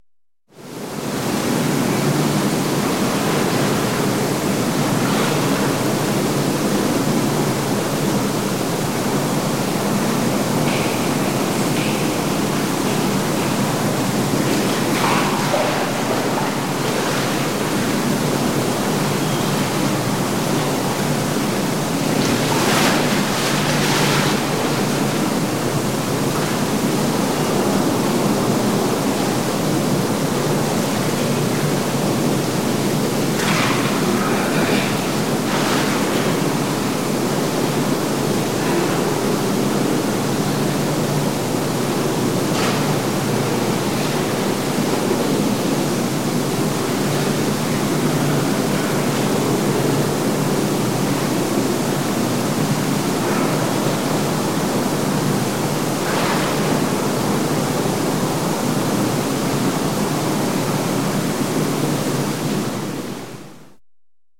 Звуки водоворота
Шум кругового течения воды в бассейне